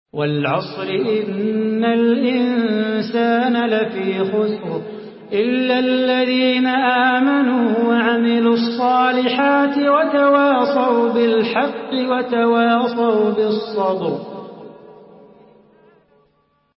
Surah العصر MP3 by صلاح بو خاطر in حفص عن عاصم narration.
مرتل